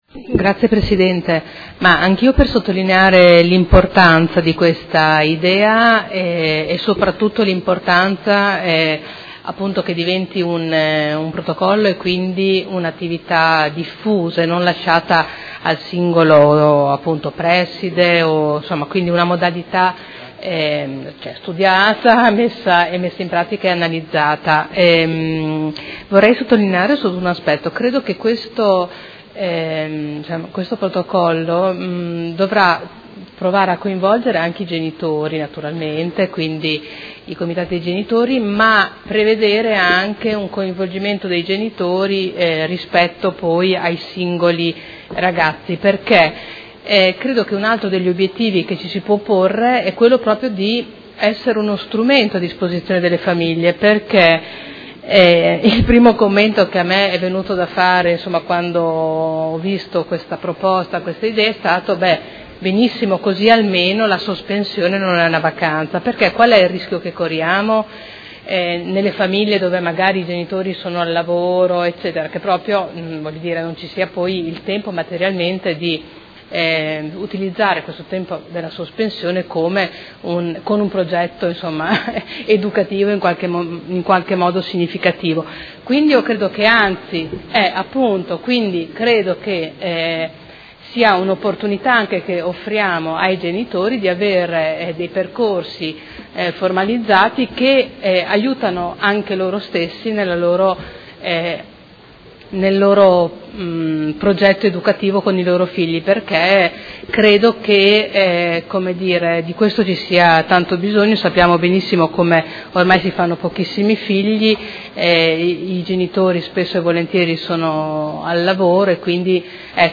Caterina Liotti — Sito Audio Consiglio Comunale